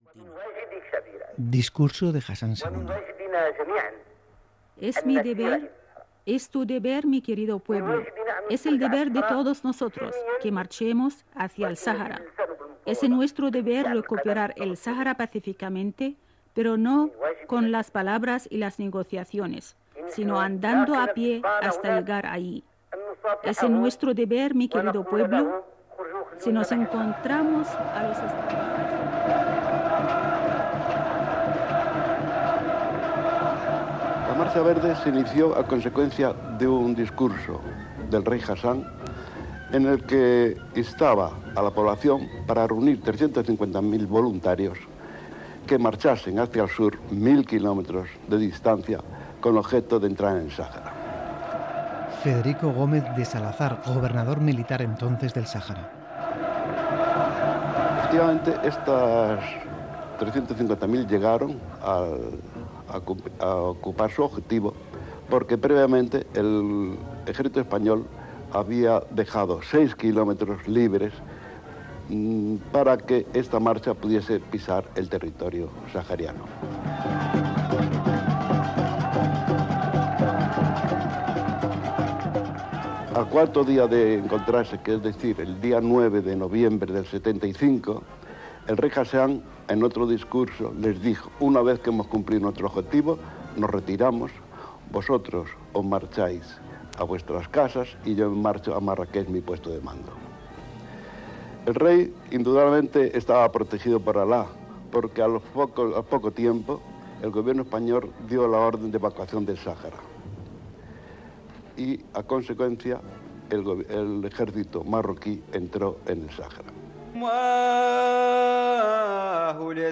Reportatge sobre com es va originar "La marxa verda" impulsada pel rei Hassan II, el 6 de novembre de 1975, per entrar al Sahara espanyol
Informatiu